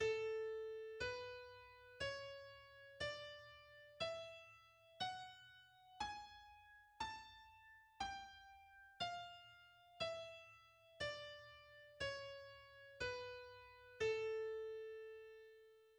A major is a major scale based on A, with the pitches A, B, C, D, E, F, and G. Its key signature has three sharps.
The A major scale is:
The A harmonic major and melodic major scales are: